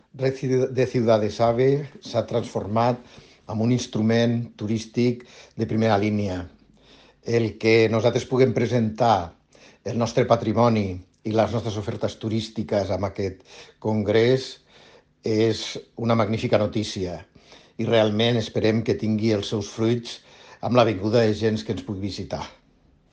El tinent d’alcalde i president de Turisme de Lleida, Paco Cerdà, ha valorat molt positivament aquest II Travel Meeting, perquè és un excel·lent aparador per donar a conèixer els nostres tresors patrimonials. Tall de veu P. Cerdà Des de la Xarxa de Ciutats AVE s'ofereix un servei únic, posant a la disposició dels clients productes i experiències.